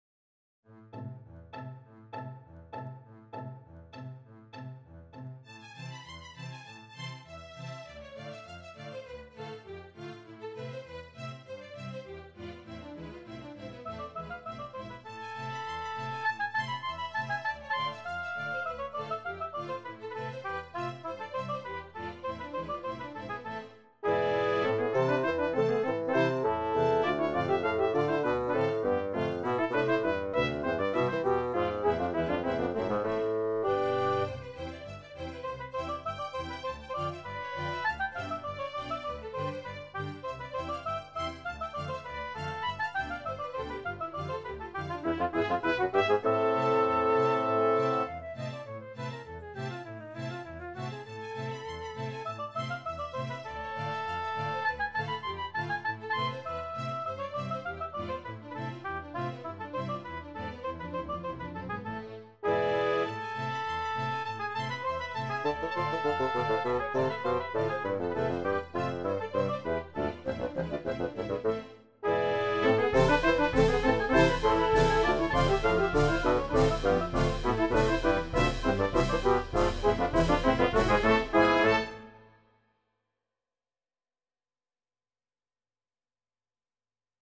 Playback courtesy of Sibelius Sound Essentials.
Thingamabob in A Minor  (Kind of silly, I admit.)